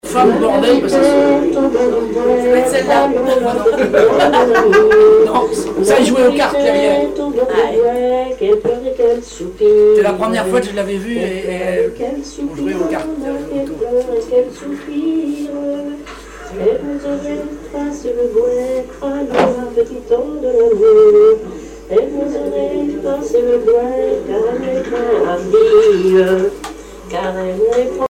Genre laisse
Chansons et commentaires
Pièce musicale inédite